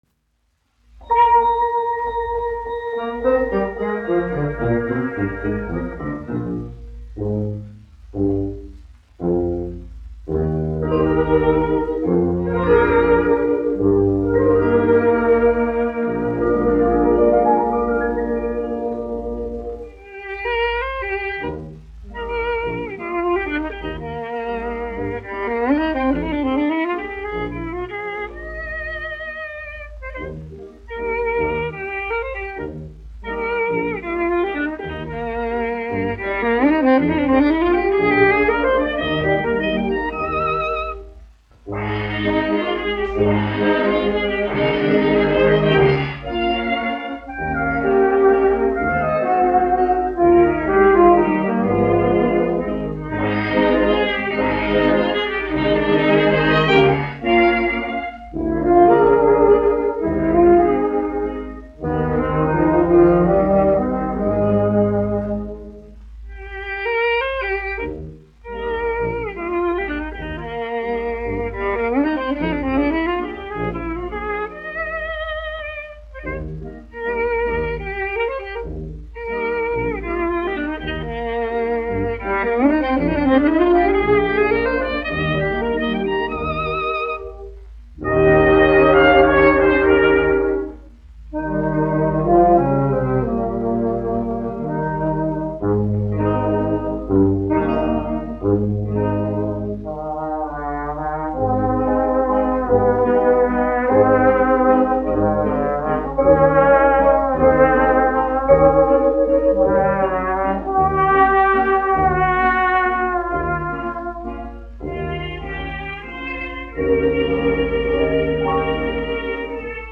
1 skpl. : analogs, 78 apgr/min, mono ; 25 cm
Orķestra mūzika
Latvijas vēsturiskie šellaka skaņuplašu ieraksti (Kolekcija)